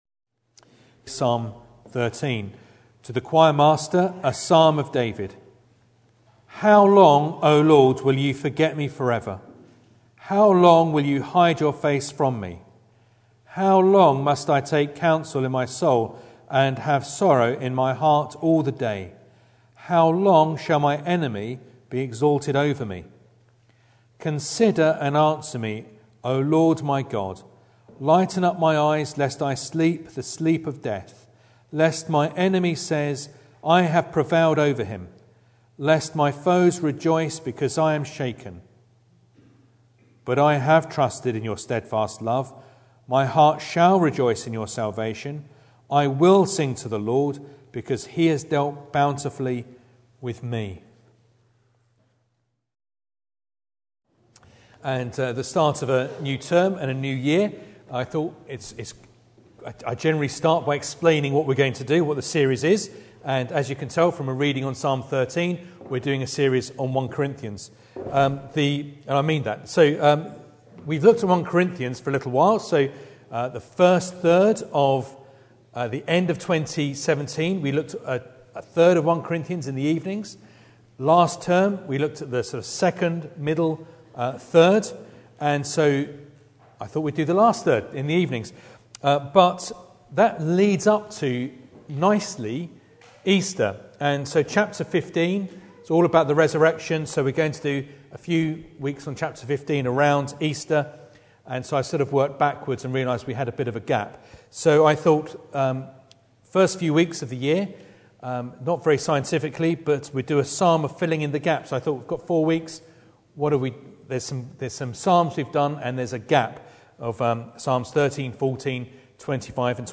Psalm 13 Service Type: Sunday Evening Bible Text